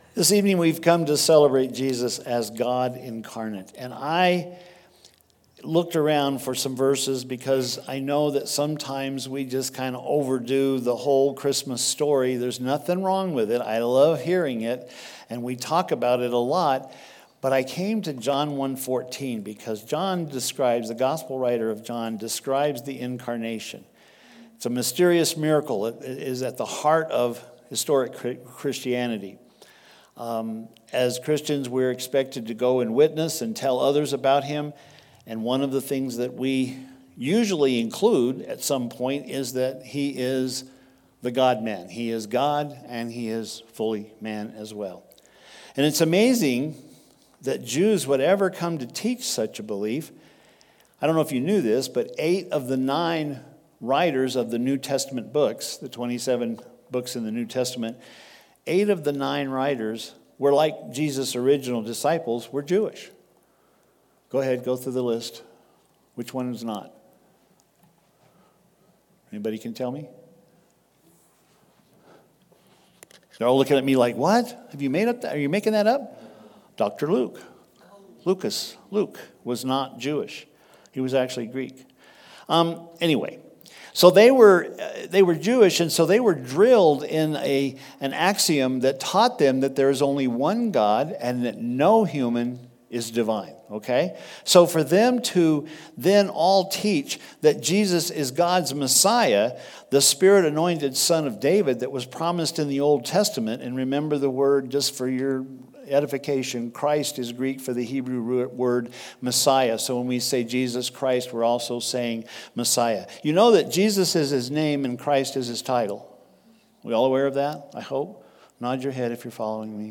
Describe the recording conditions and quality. The message for the Christmas Eve Candlelight Service, looking at the Messiah as He is described in John 1:14.